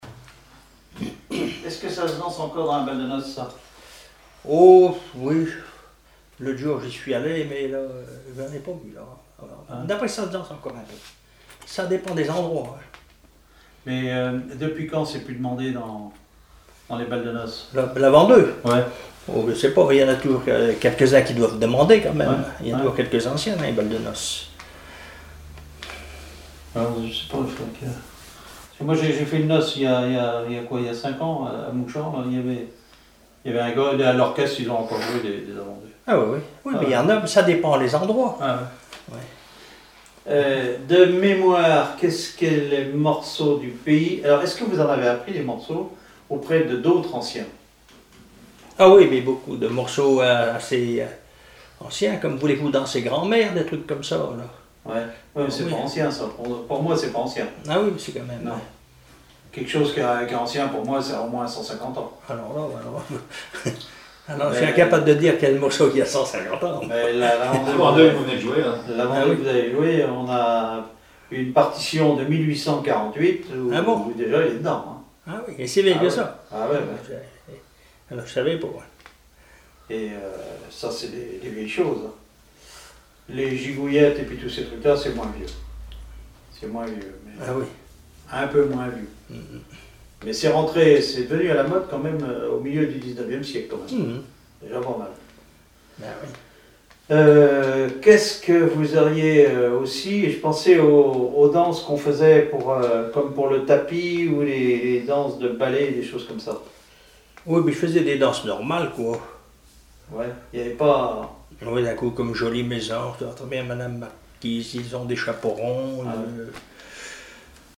musicien(s) ; accordéon(s), accordéoniste
Catégorie Témoignage